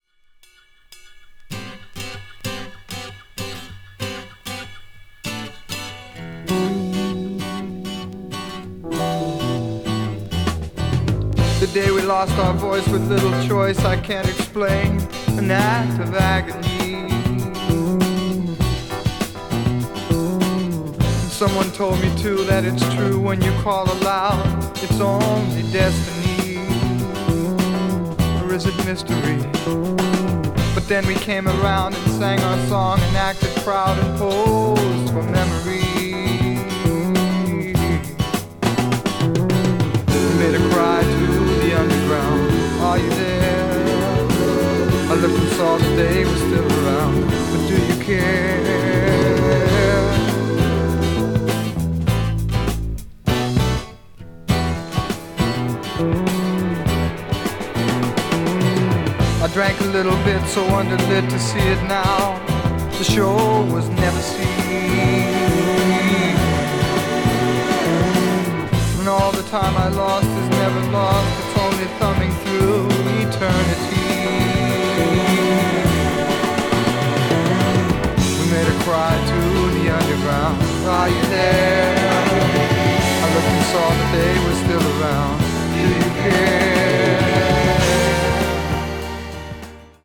media : EX/EX(some slightly noises.)